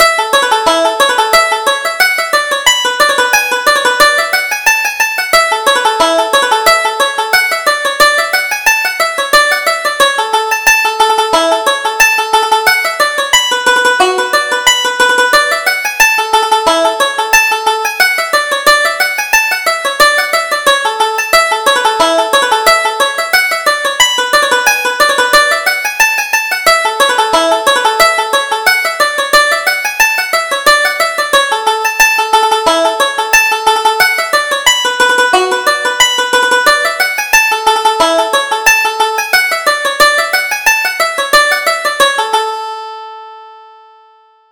Reel: The Maids of Tulla